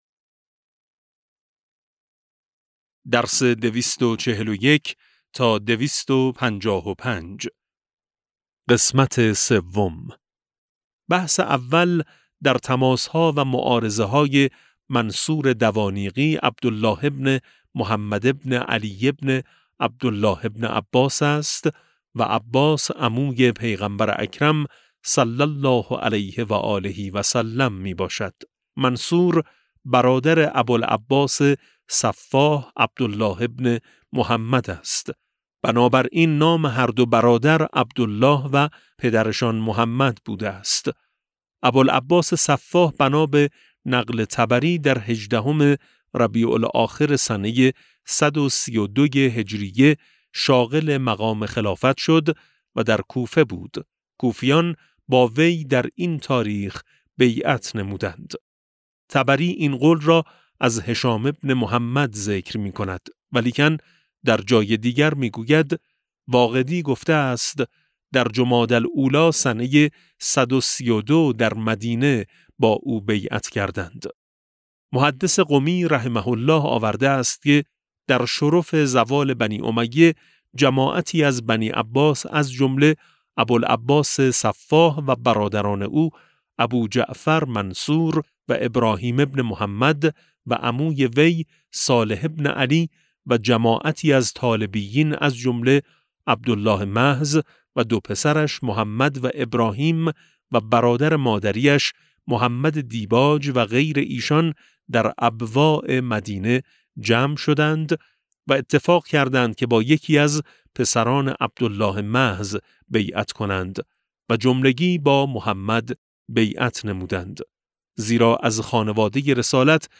کتاب صوتی امام شناسی ج 16 و17 - جلسه14